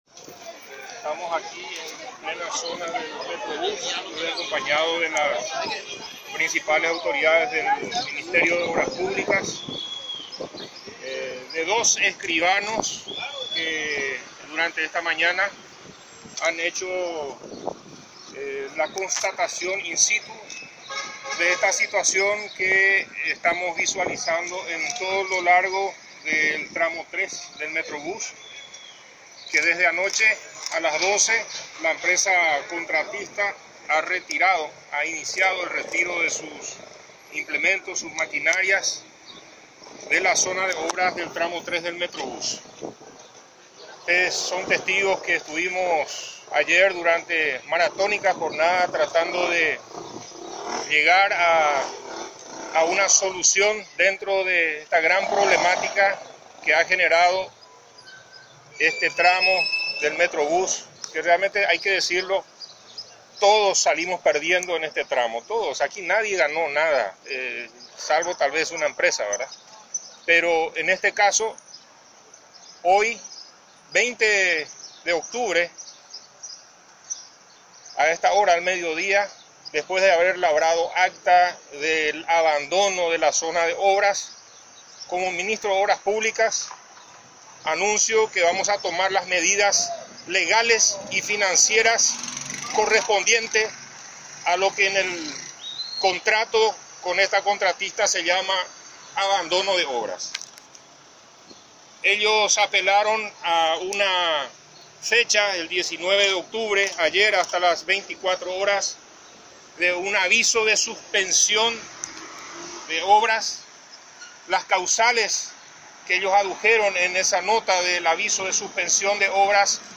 El titular de la cartera estatal se quebró durante sus declaraciones.